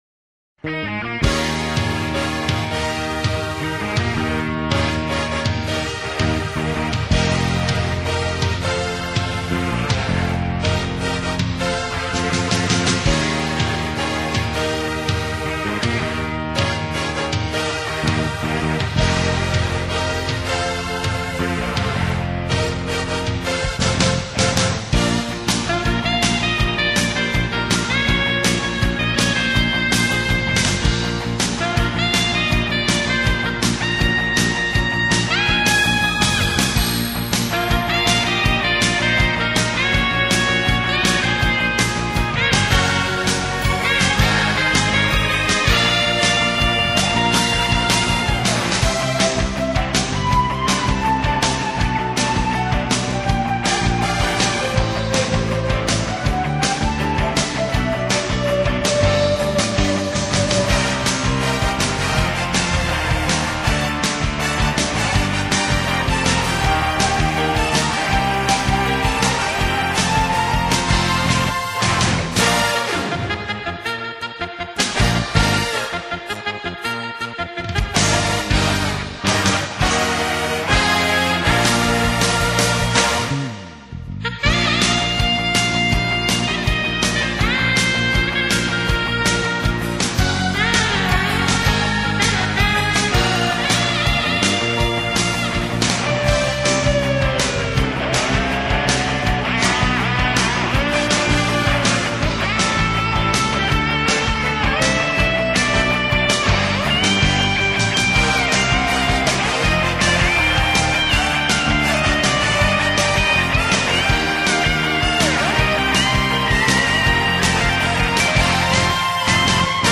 (오케스트라 사운드가 거의 사용되지 않았으며 기계적인 사운드에만 의존.)
빠르고 경쾌한 분위기가 괜찮은 곡입니다.